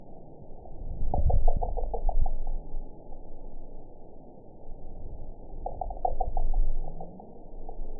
event 922006 date 12/25/24 time 02:35:53 GMT (11 months, 1 week ago) score 5.96 location TSS-AB03 detected by nrw target species NRW annotations +NRW Spectrogram: Frequency (kHz) vs. Time (s) audio not available .wav